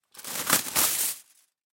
Звук разрыва фольги пополам